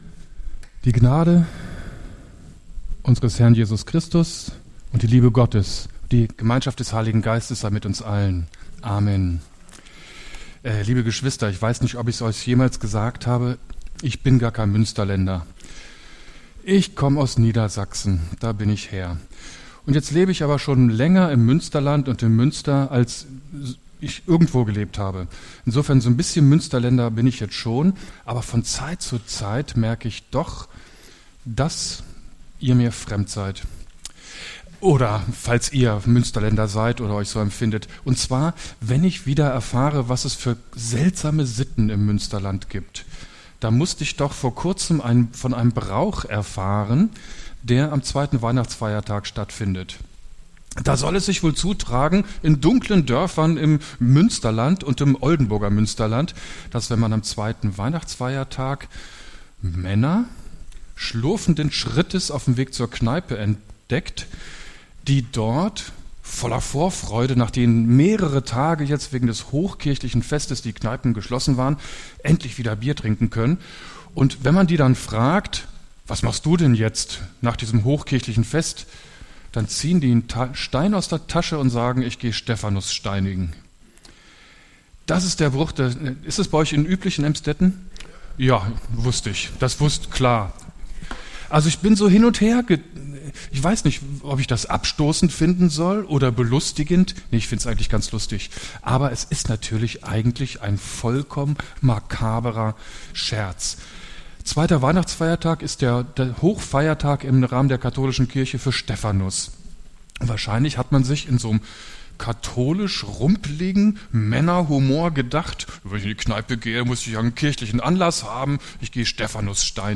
Aktuelle Predigten der Markusgemeinde Emsdetten; Wir feiern jeden Sonntag in der Martin-Luther-Kirche in Emsdetten (Neubrückenstrasse 96) unseren Gottesdienst.